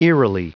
Prononciation du mot eerily en anglais (fichier audio)